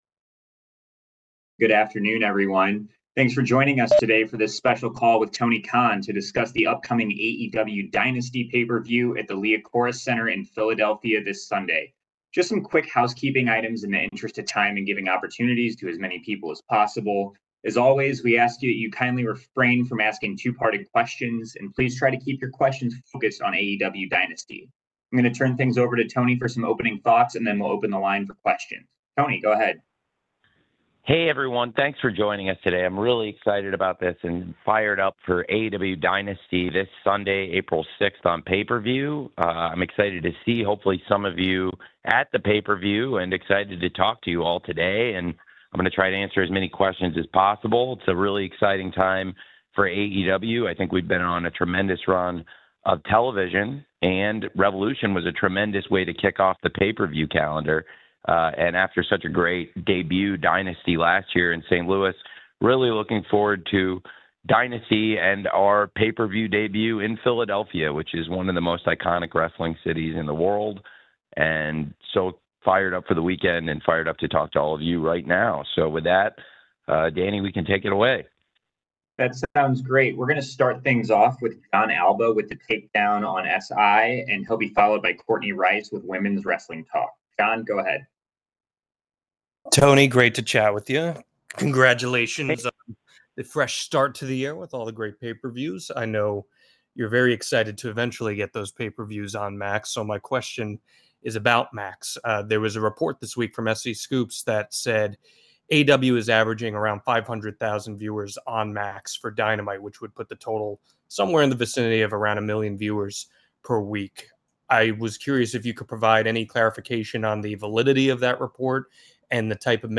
Tony Khan spoke at length to the media about the upcoming Dynasty PPV, Prime Video, media rights, streaming numbers and free agents.
AEW-Dynasty-Media-Briefing-4-3-25.mp3